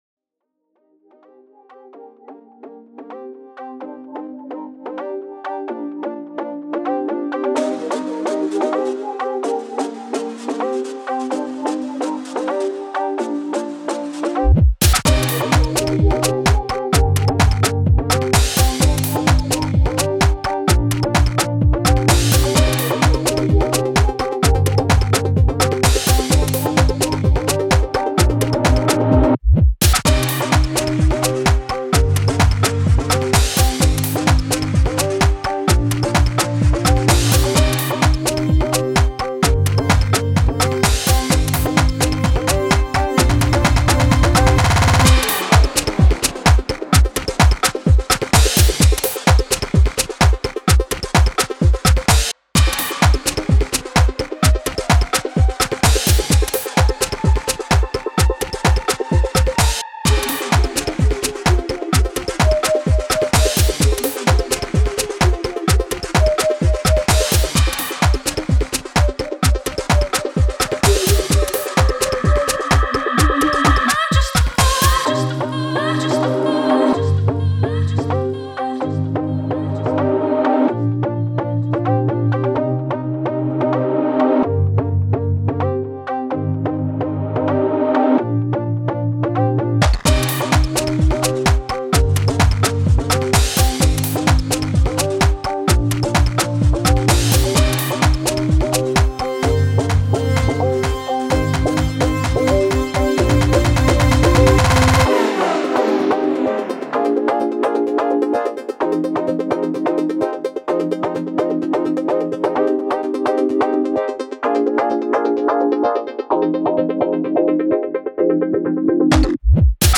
• 酷炫的电子音乐，co: You are a genius！
• 大师这次少了迷幻，多了动感舞曲风，精彩！
节奏轻快